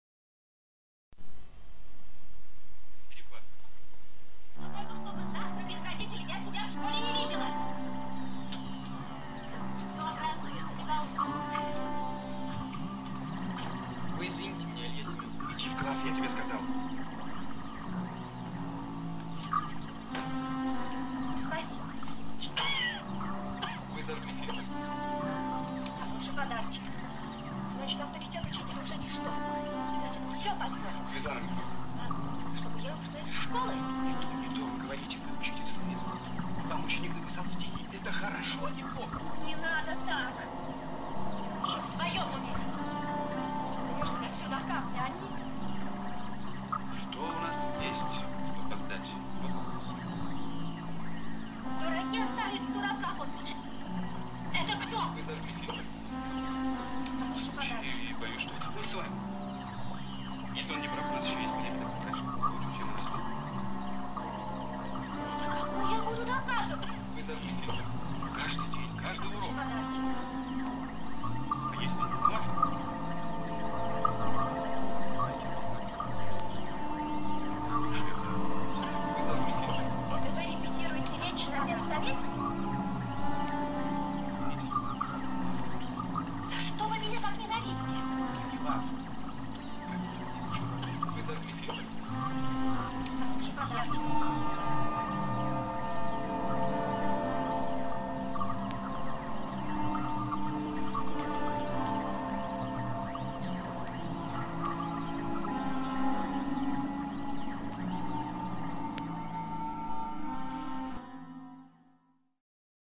вокал, гитара